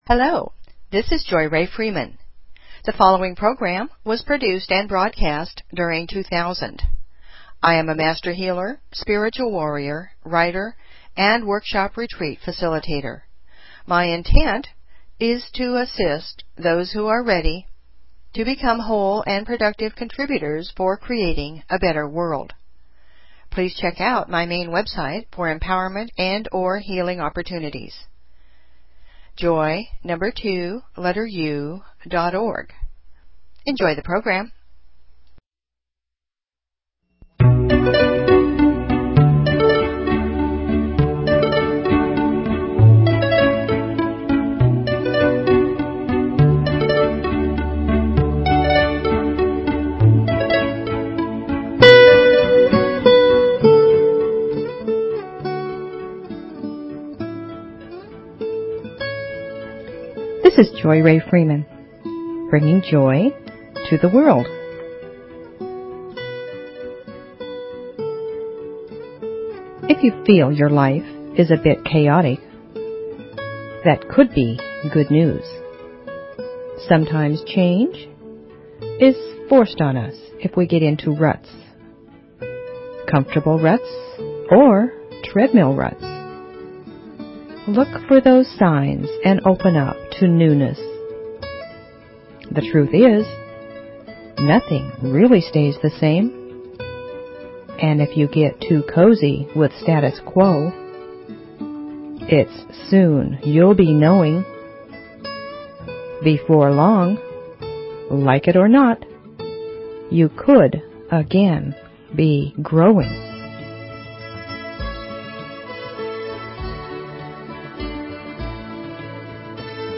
Talk Show Episode, Audio Podcast, Joy_To_The_World and Courtesy of BBS Radio on , show guests , about , categorized as
Music, poetry, affirmations, stories, inspiration . . .
It's lively, but not rowdy - it's sometimes serious, but not stuffy - it's a little funny, but not comical - and most of all - it's a passionate, sincere sharing from my heart to yours.